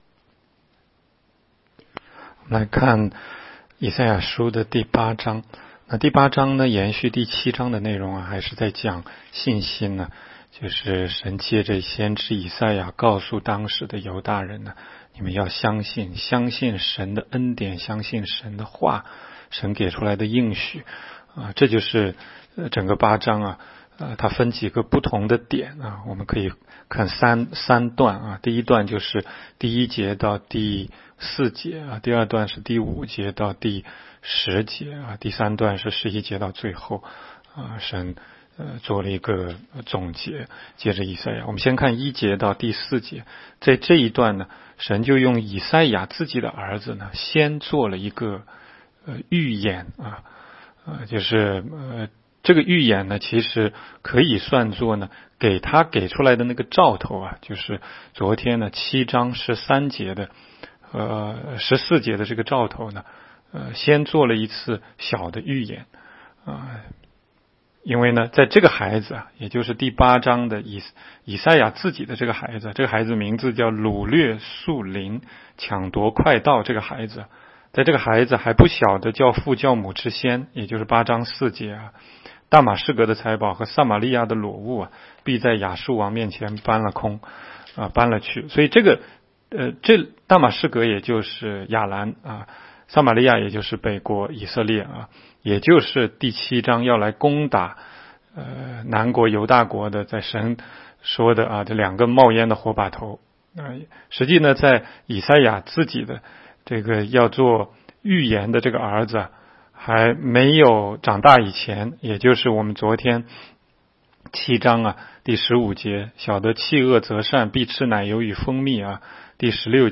16街讲道录音 - 每日读经 -《 以赛亚书》8章